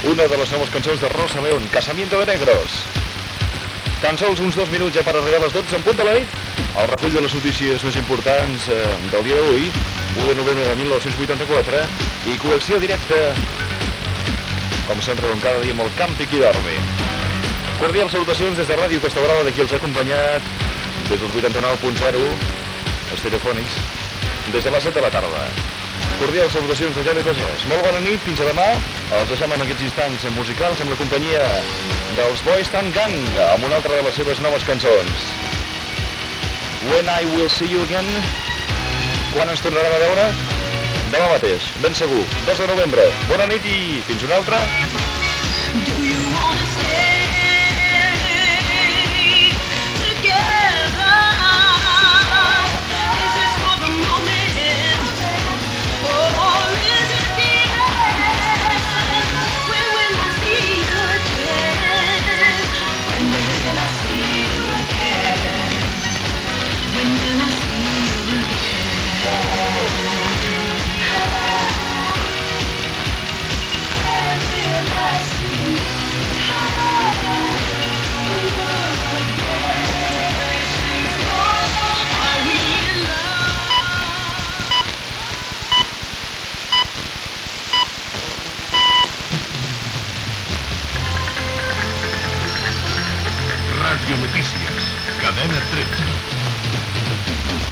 cc91eda5f7a11ee4c8e1ae901b7631c1bf9ed7f9.mp3 Títol Ràdio Costa Brava Emissora Ràdio Costa Brava Cadena Cadena 13 Titularitat Privada local Descripció Comiat del programa, música i careta Radionotícies de la Cadena 13.